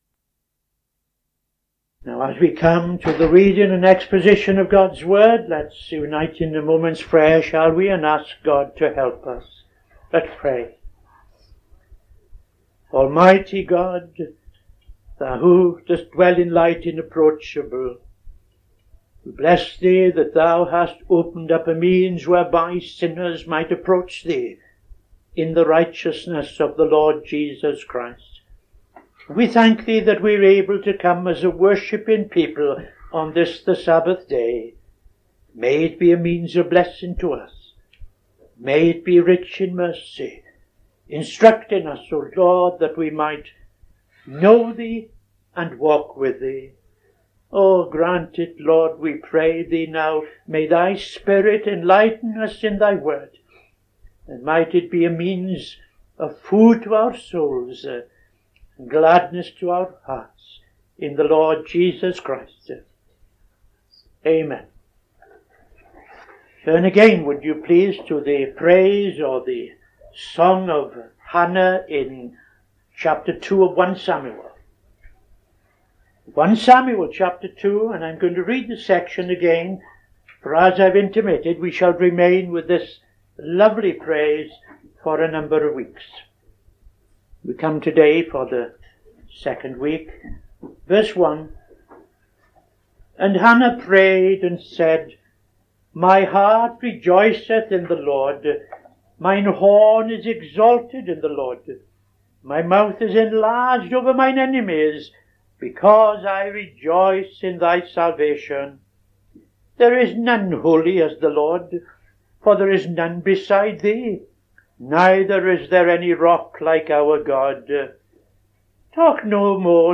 Bible Study - TFCChurch